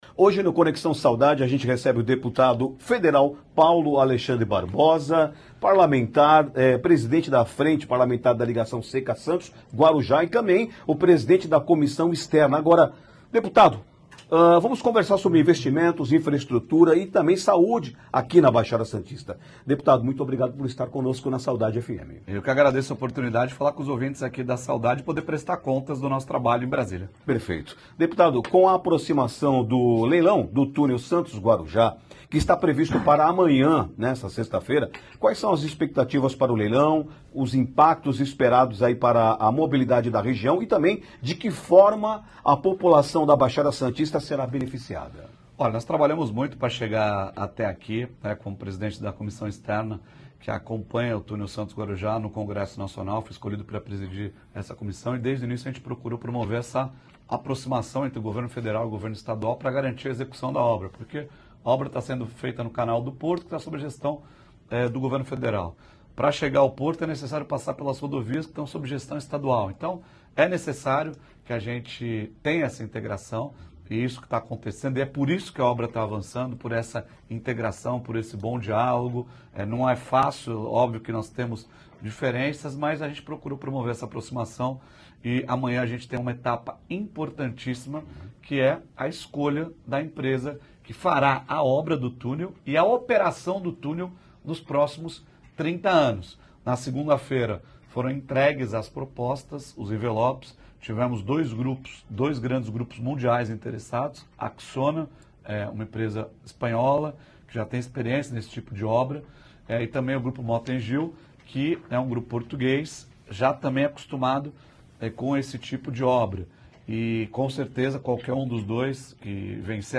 Em entrevista na Saudade,deputado Paulo Alexandre aborda investimentos e infraestrutura na Baixada Santista – SAUDADE FM
Ontem, no Conexão Saudade, o deputado Paulo Alexandre Barbosa foi o convidado para falar sobre temas importantes para a região da Baixada Santista, incluindo investimentos, infraestrutura e saúde.